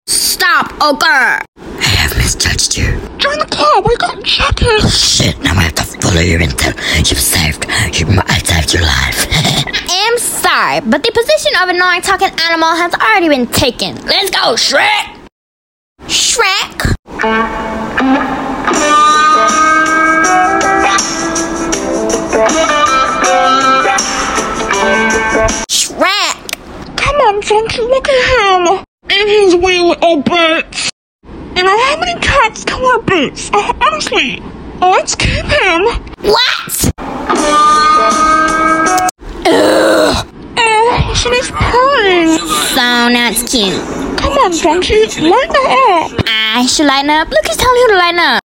Isnt Pusses Purring So Cute Sound Effects Free Download